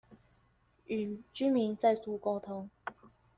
Тайваньский 507